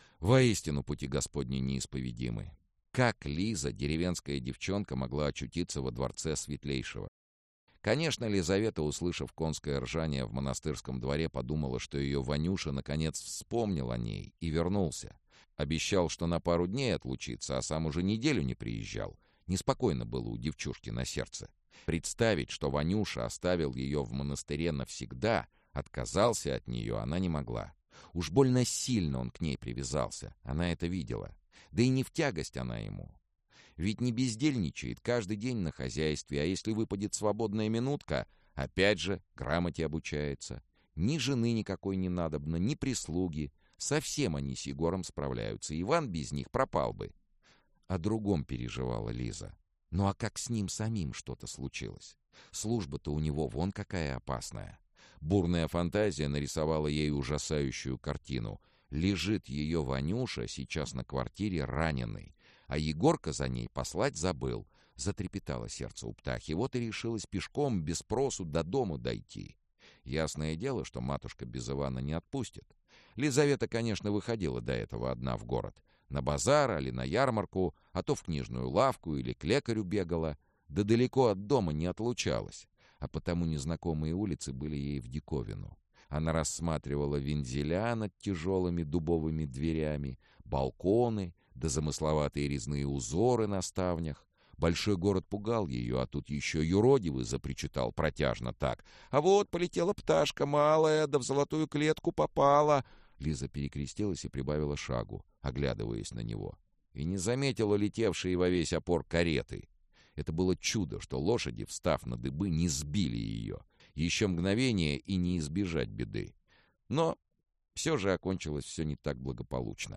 Аудиокнига Записки экспедитора Тайной канцелярии. Пропавшее завещание | Библиотека аудиокниг
Пропавшее завещание Автор Олег Рясков Читает аудиокнигу Сергей Чонишвили.